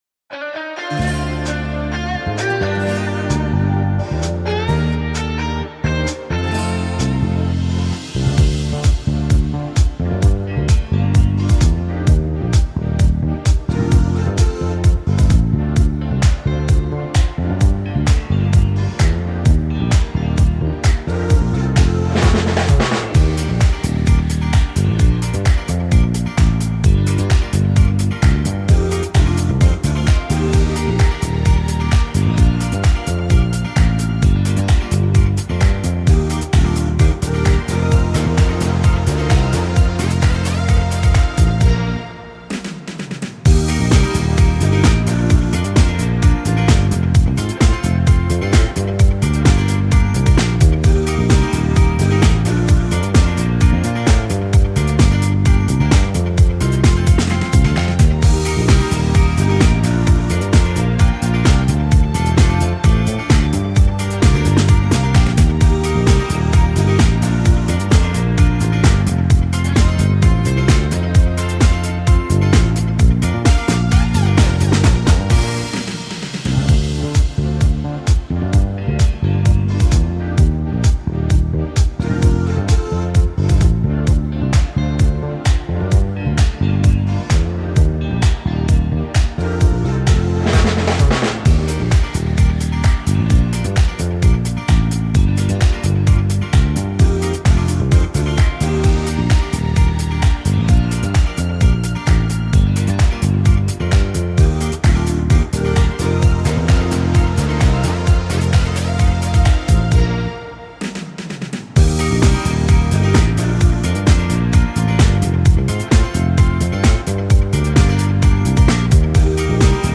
караоке
минусовка